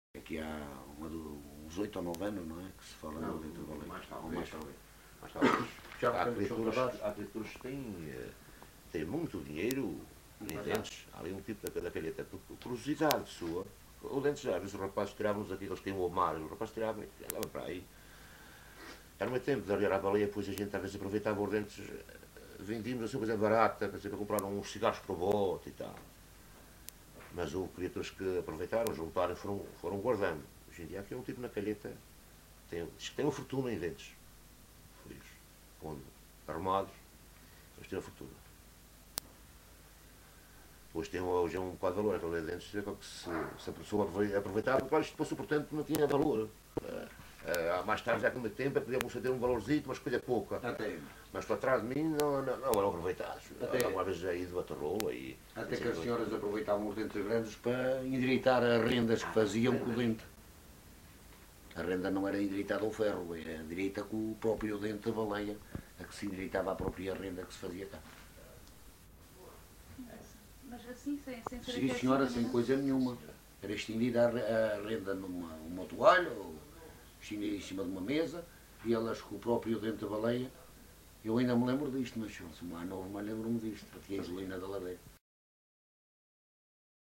LocalidadeRibeiras (Lages do Pico, Horta)